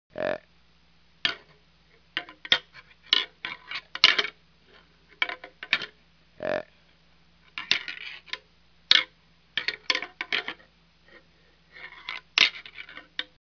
Звук схватки лани в спарринге